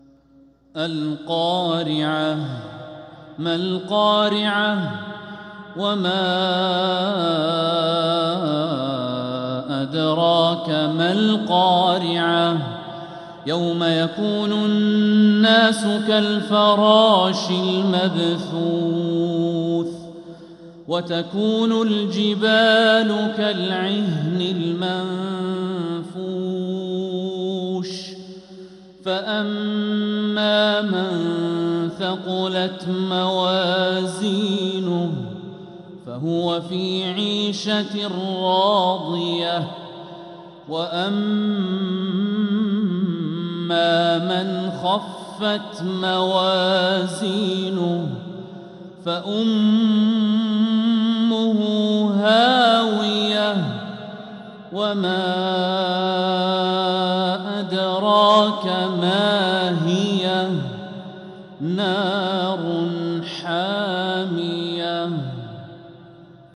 سورة القارعة | فروض جمادى الآخرة 1446هـ > السور المكتملة للشيخ الوليد الشمسان من الحرم المكي 🕋 > السور المكتملة 🕋 > المزيد - تلاوات الحرمين